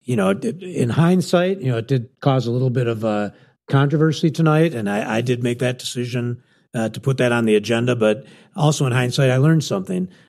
AUDIO: Scientology debate breaks out at Portage City Council meeting
City Manager Pat McGinnis approved putting it on the agenda.